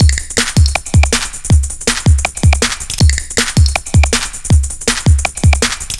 Index of /90_sSampleCDs/Zero-G - Total Drum Bass/Drumloops - 1/track 12 (160bpm)